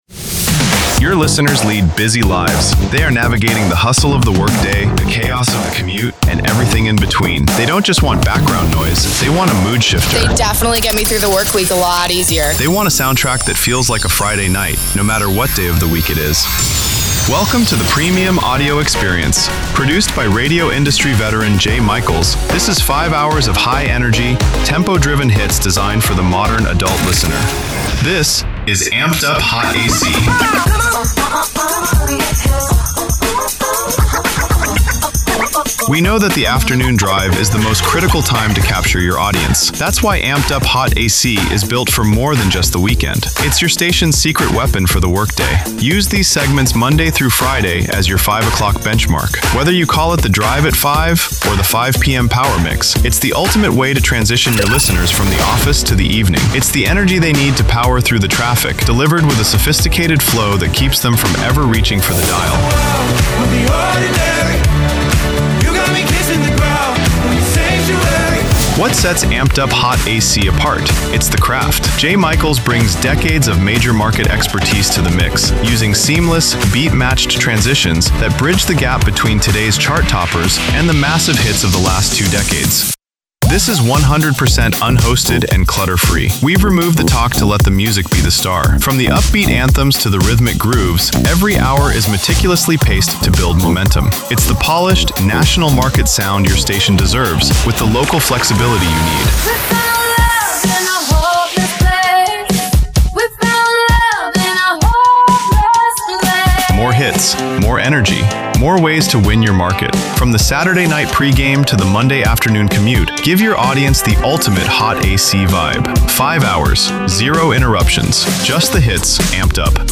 Hot AC